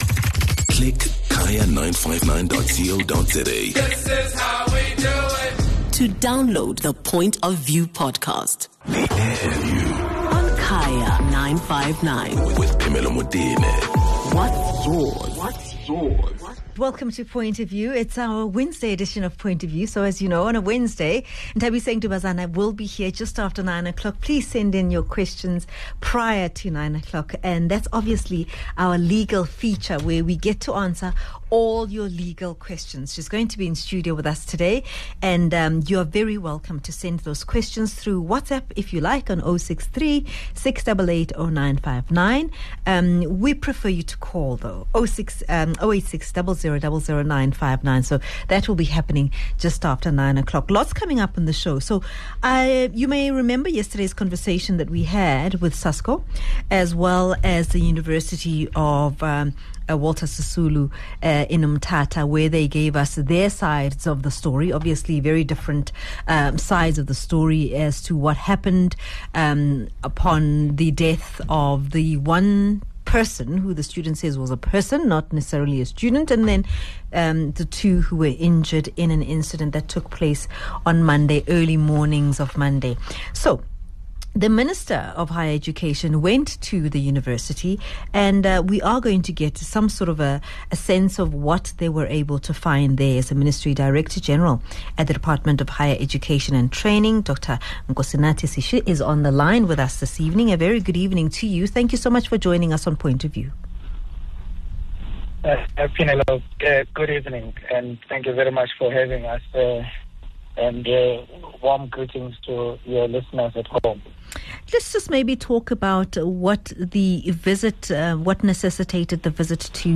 speaks to Director General at the Department of Higher Education and Training, Dr Nkosinathi Sishi.